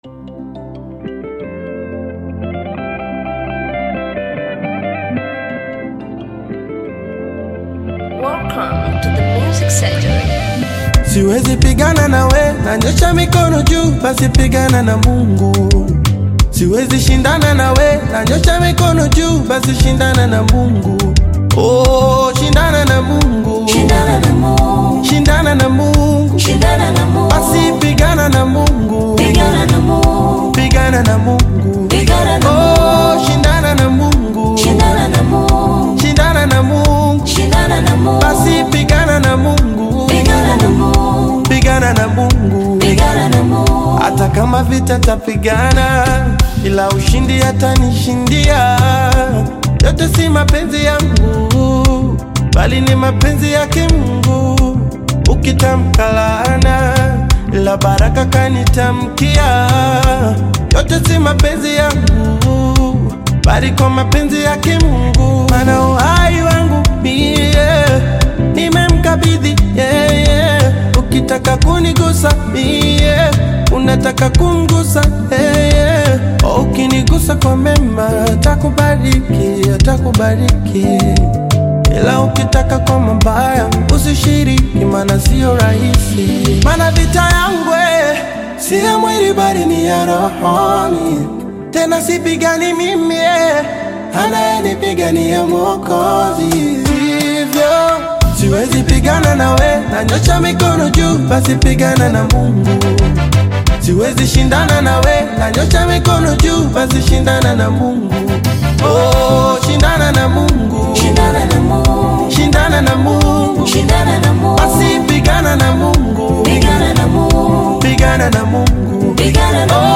Tanzanian Gospel artist, singer and songwriter
Kenyan gospel artist
You can also find more Nyimbo za Dini songs below.